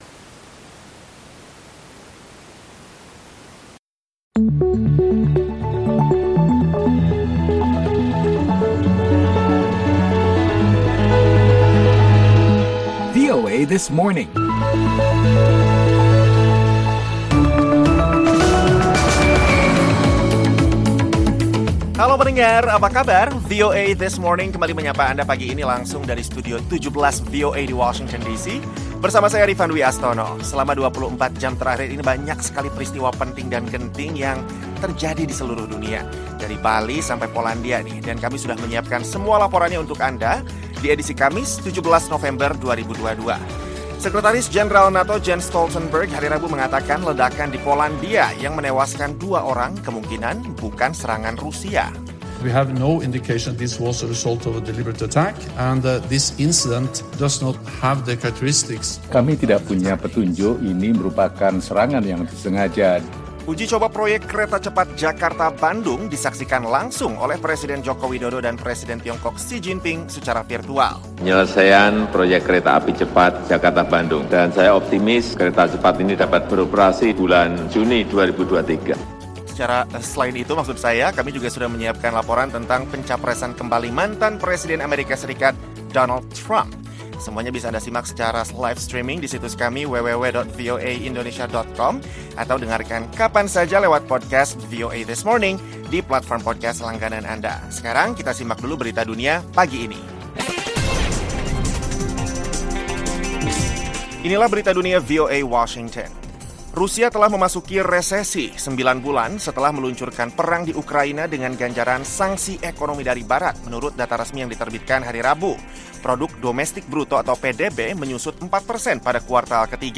Awali aktivitas pagi hari Anda dengan VOA This Morning bersama tim VOA langsung dari Washington, D.C. setiap Senin-Jumat. Program ni menghadirkan serangkaian berita dan informasi menarik dari Indonesia, Amerika dan dunia.